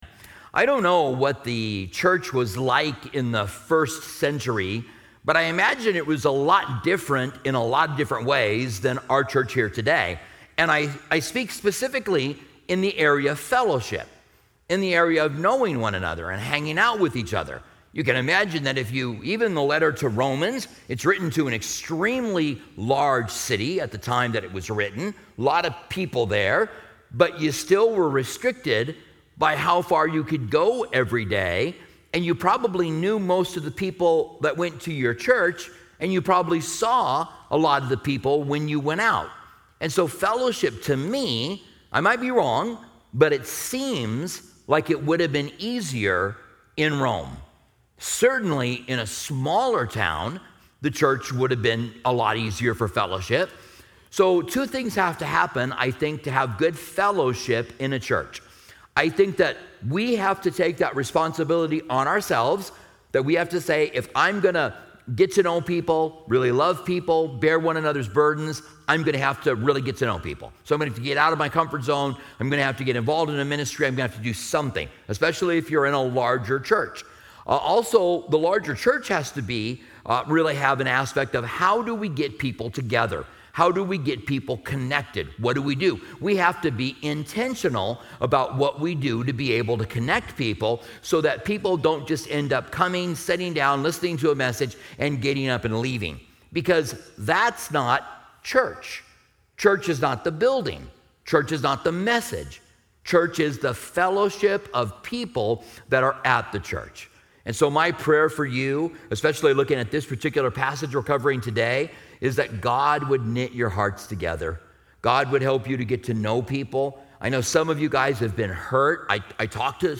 40:02 Closing Prayer